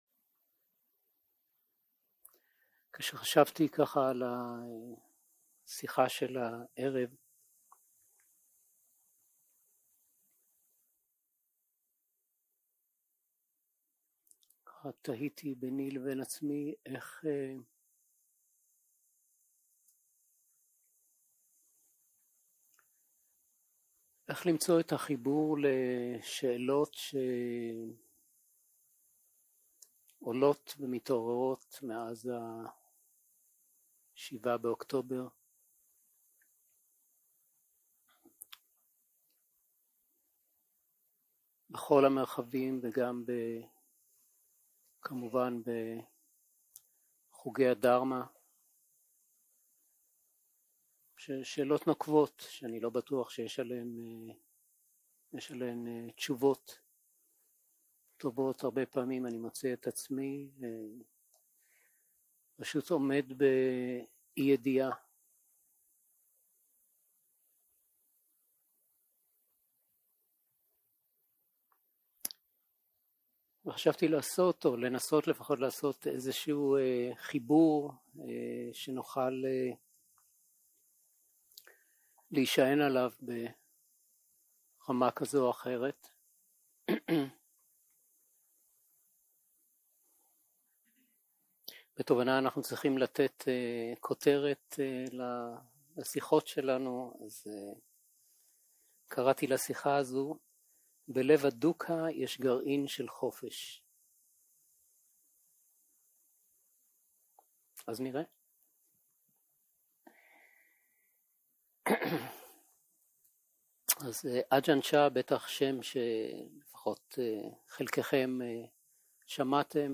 יום 2 - הקלטה 4 - ערב - שיחת דהרמה - בלב הדוקהה יש גרעין של חופש | תובנה